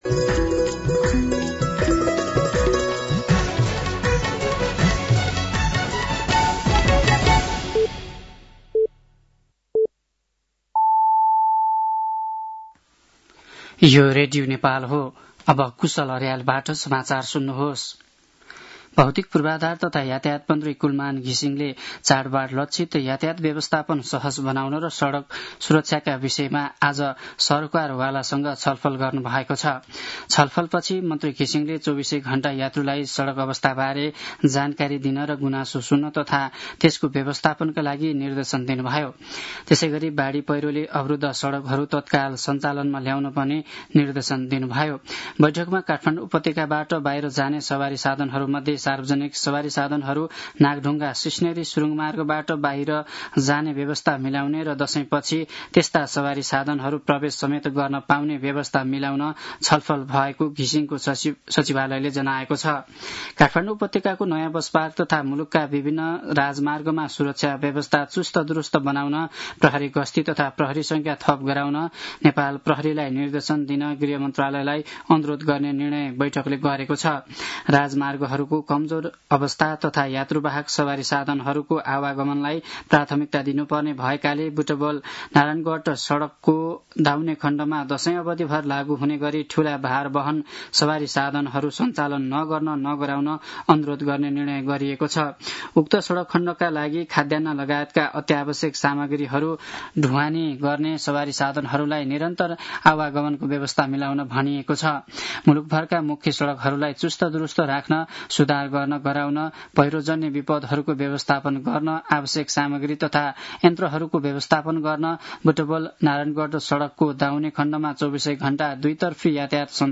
साँझ ५ बजेको नेपाली समाचार : ४ असोज , २०८२